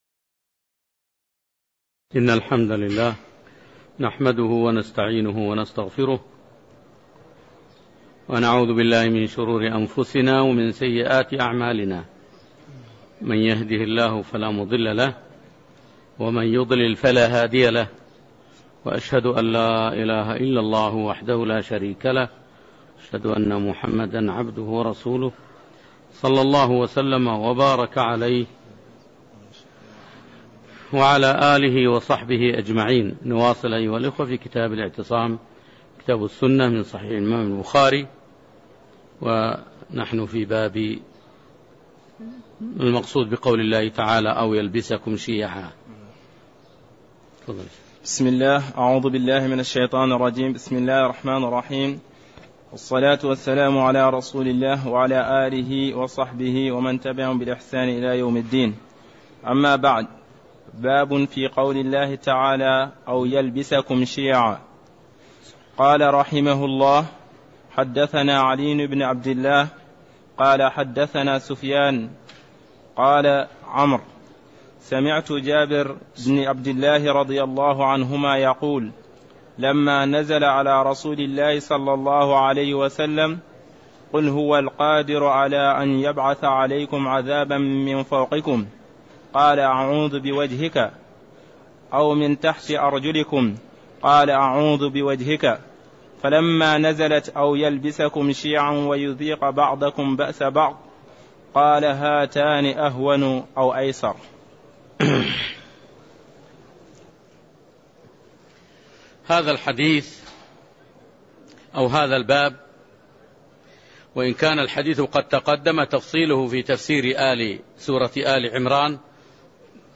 تاريخ النشر ٤ جمادى الأولى ١٤٣١ هـ المكان: المسجد النبوي الشيخ